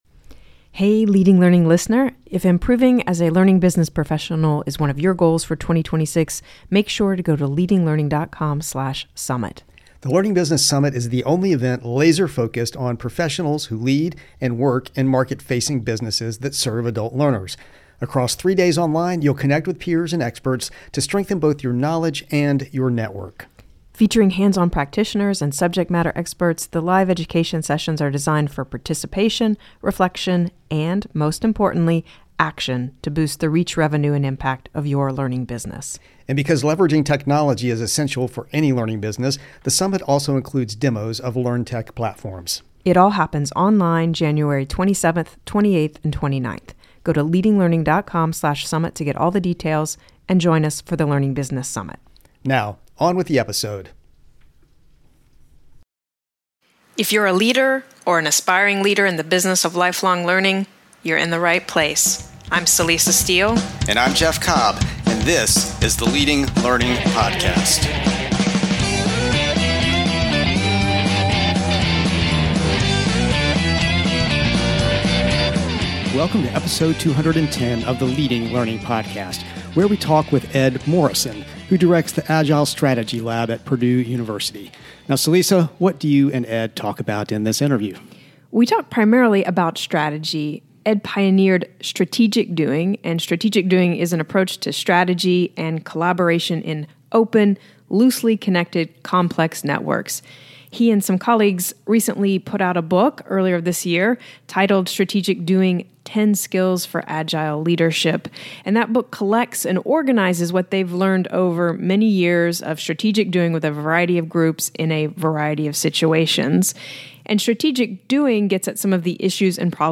Note: We re-aired this episode interview in January 2024.